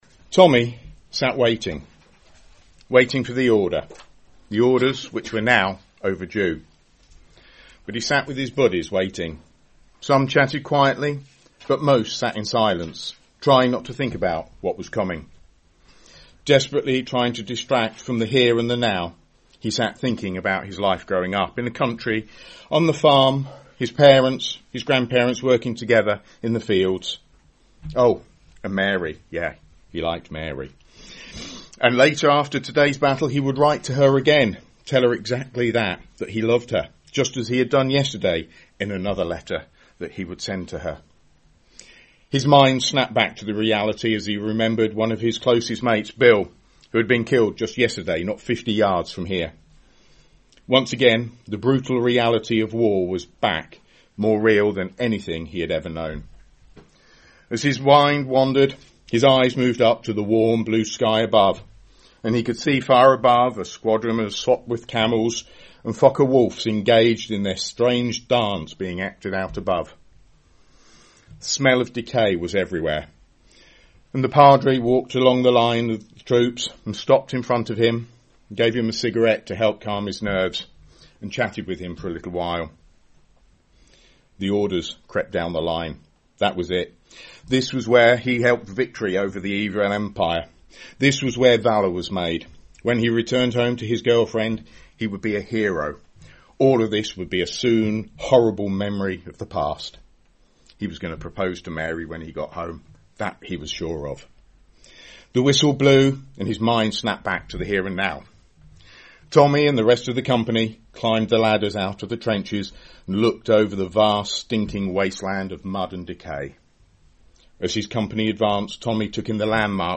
Sermonette